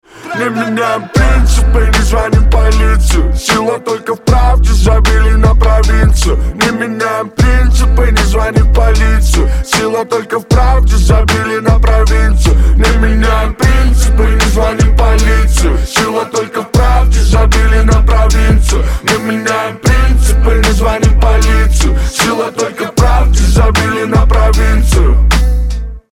Новый пацанский рэп